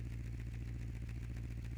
carEngine.wav